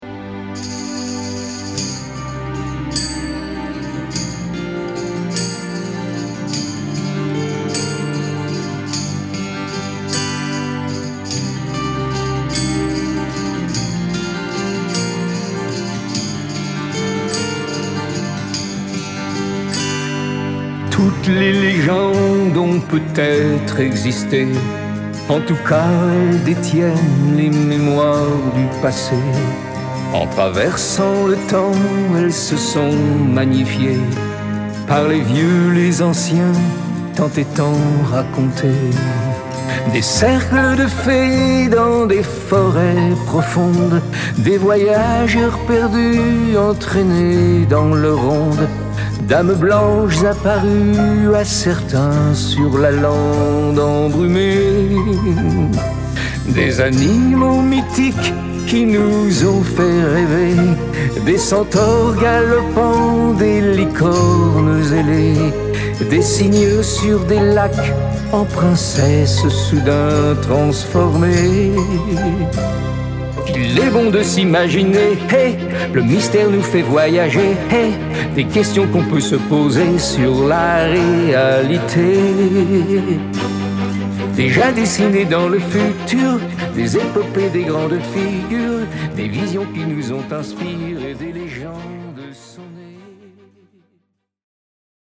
exprimées en ballades, jazz, bossa-novas, pop